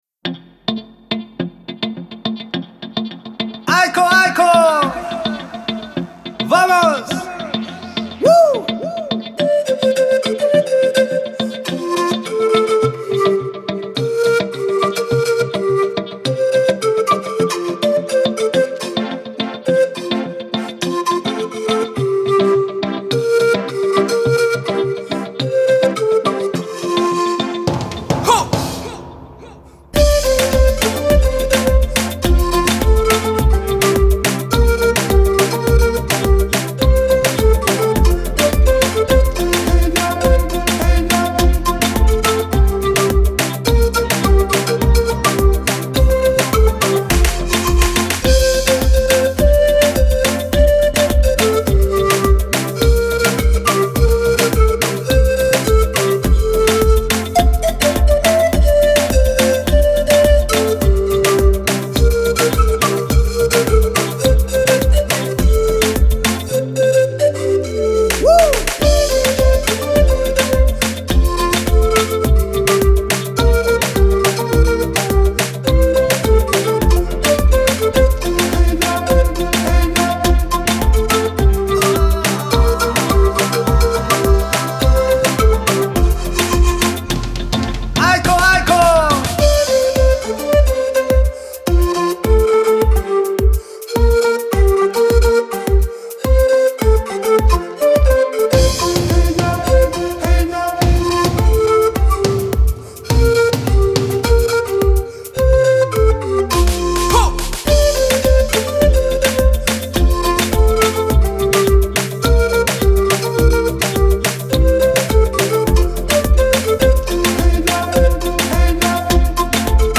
امید‌بخش , پر‌انرژی
موسیقی بی کلام پن فلوت موسیقی بی کلام سرخپوستی